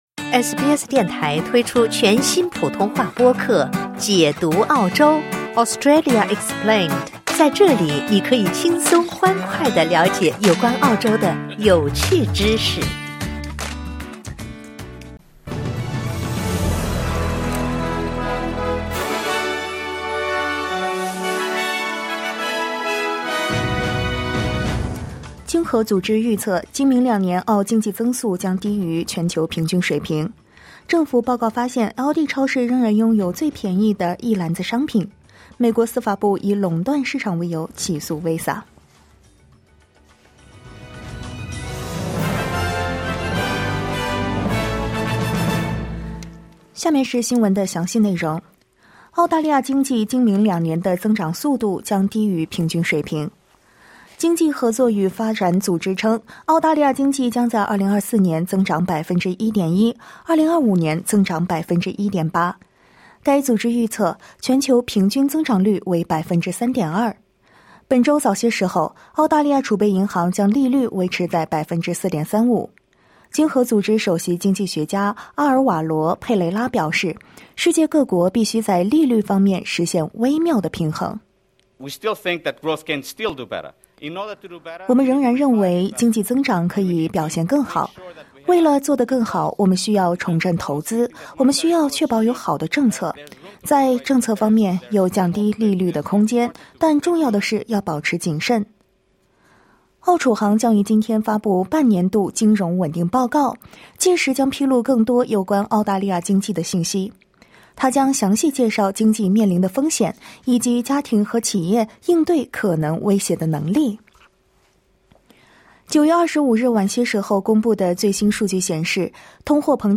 SBS早新闻（2024年9月26日）